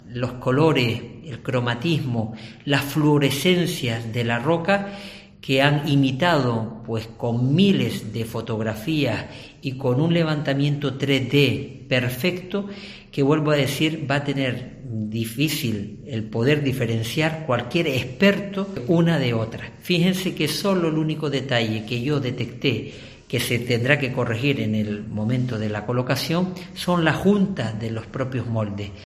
Teodoro Sosa, concejal de Patrimonio del cabildo de Gran Canaria